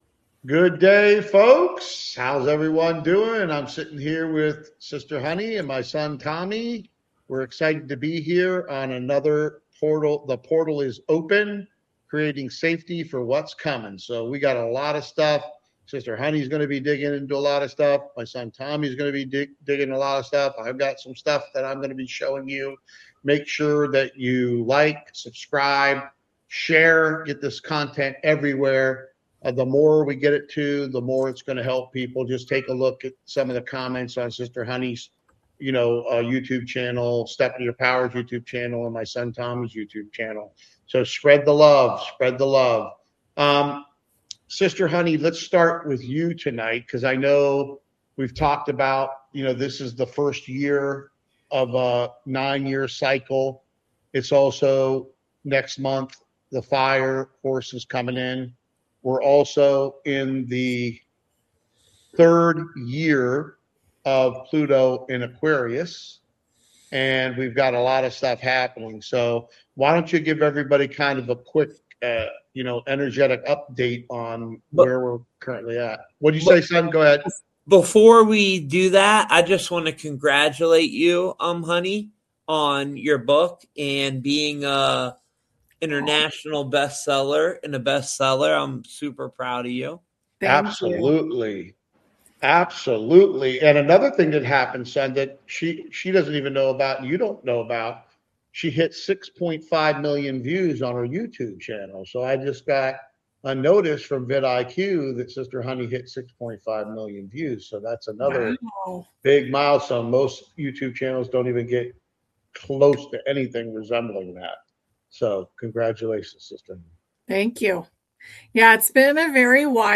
The text is a conversation between a group of people discussing various topics.